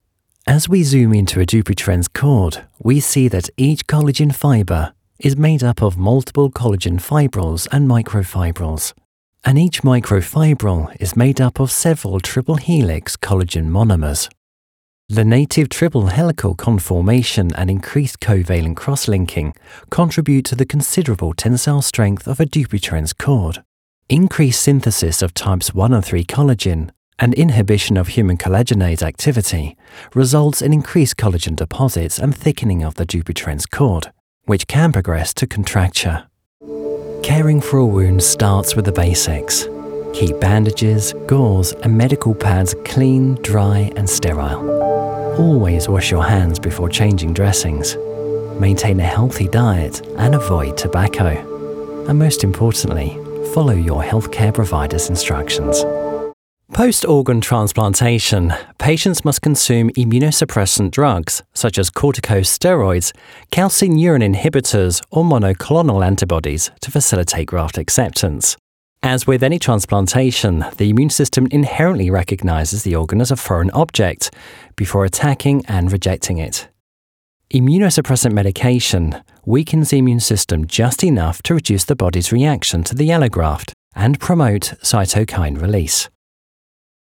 Medical Narration
Gender: Male
Description of voice: I have a neutral British accent. My voice has a fresh, clear, measured and self-assured tone. Friendly, but authoritative if needed!
Home Recording Studio
Microphones: Neumann TLM 102